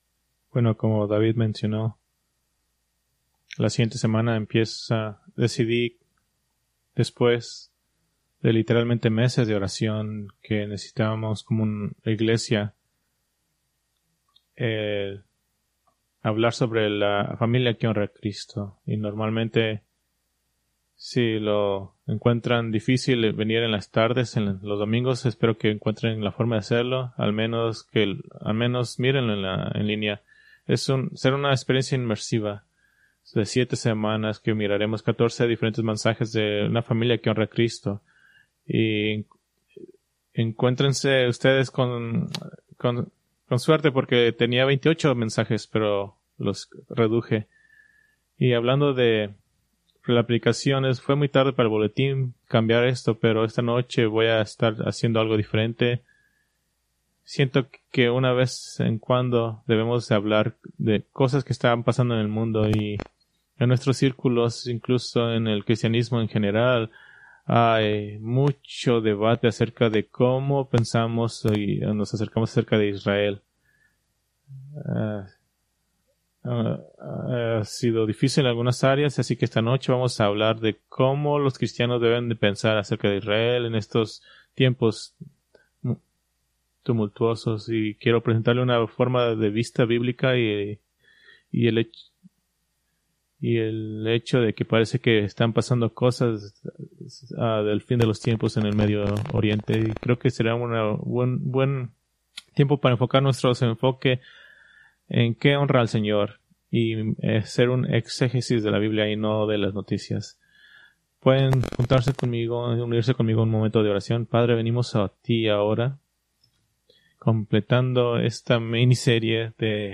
Preached June 22, 2025 from Mateo 10:40-42